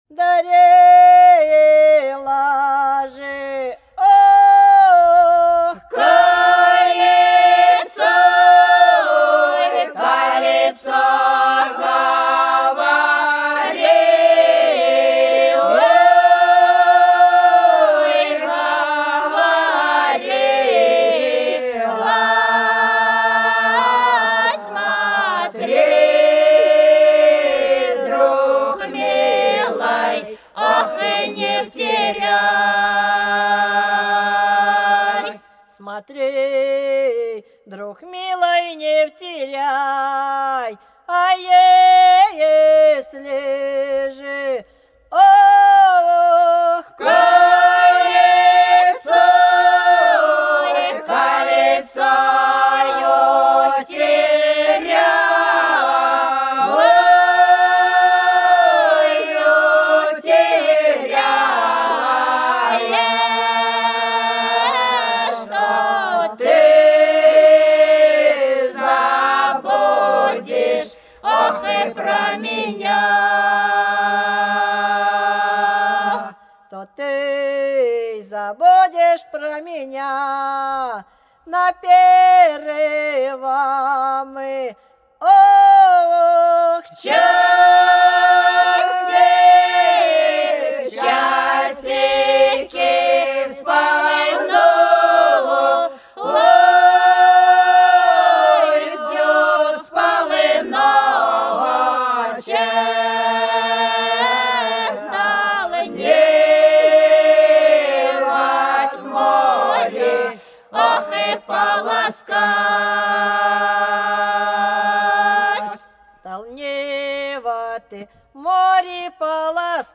За речкою диво Дарила кольцо - протяжная (с. Русская Буйловка)
18_Дарила_кольцо_-_протяжная.mp3